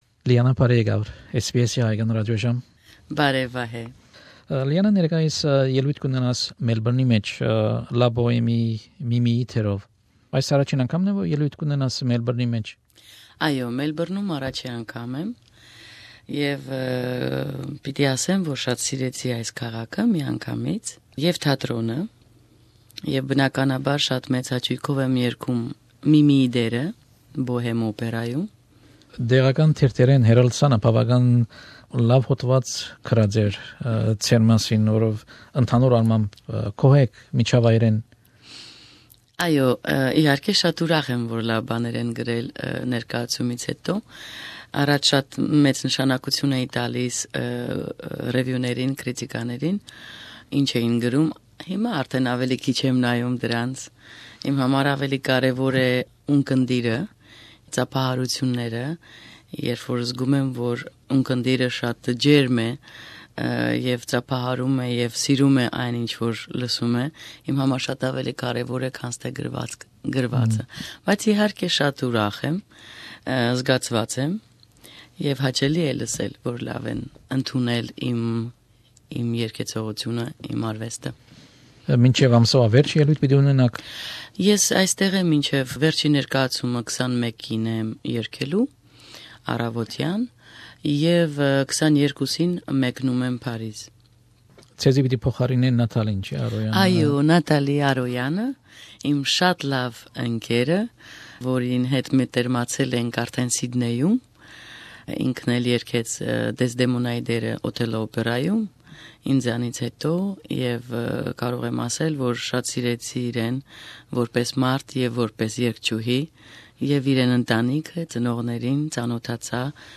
An interview with soprano Lianna Haroutounian, who is currently performing in Melbourne as Mimi in Puccini's La Boheme.